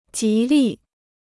吉利 (jí lì) Free Chinese Dictionary